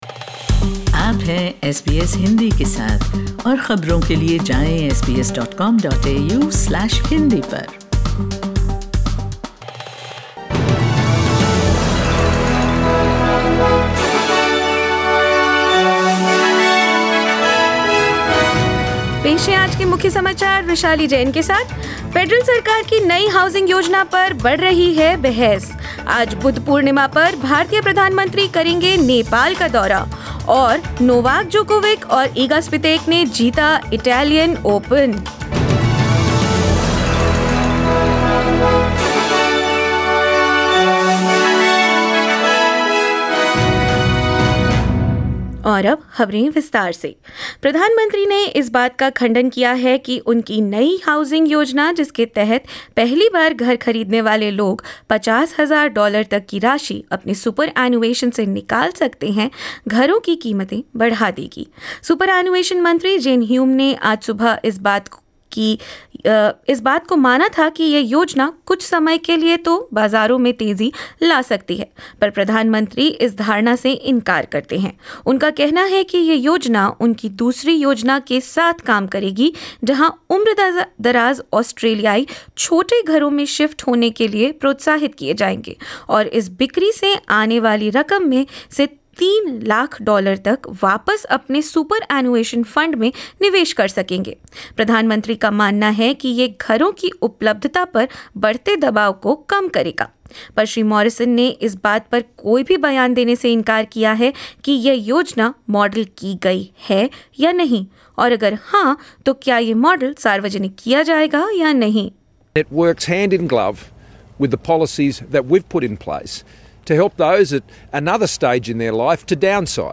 In this latest SBS Hindi bulletin: Prime Minister Scott Morrison defends Liberal's housing policy as economists and labor say it will raise the prices of houses; Indian Prime Minister Narendra Modi on a one-day tour to Nepal on the occasion of Buddha Purnima; Novak Djokovic and Poland's Iga Swiatek win the Italian Open, and more.